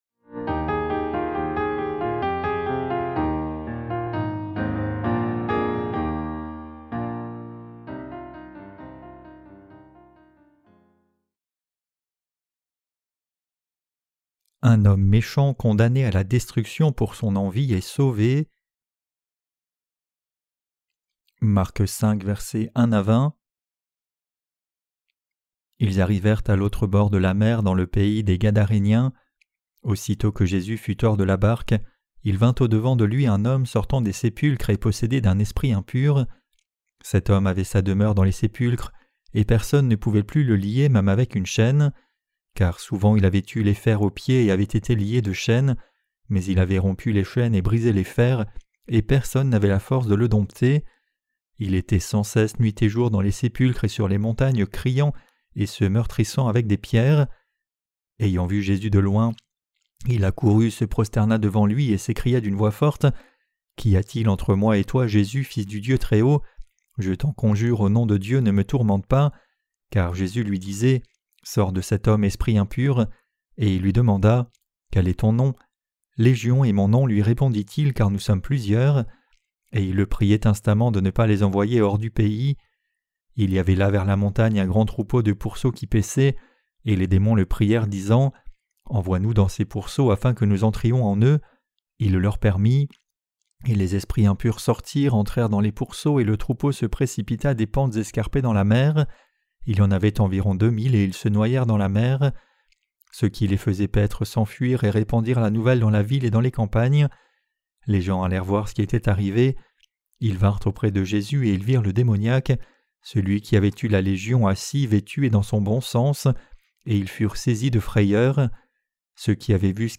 Sermons sur l’Evangile de Marc (Ⅰ) - QUE DEVRIONS-NOUS NOUS EFFORCER DE CROIRE ET PRÊCHER? 12.